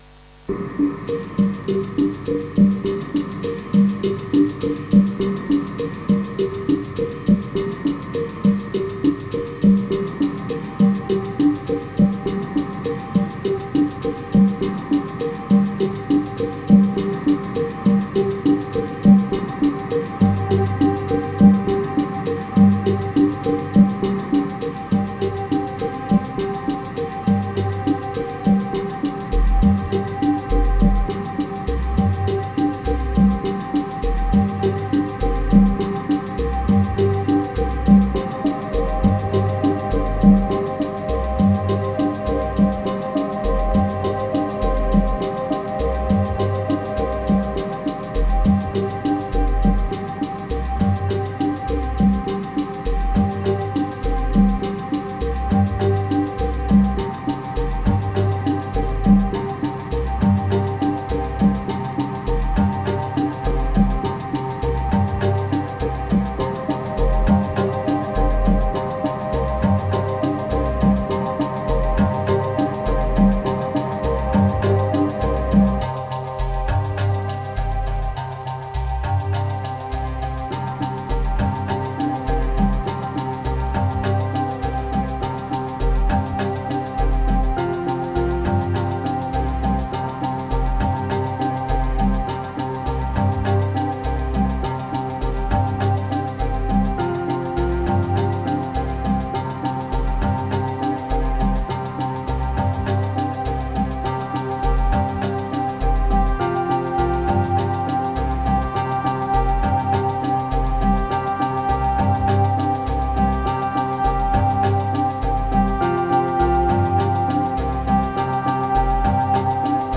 Prise du matin avec le soleil qui se lève.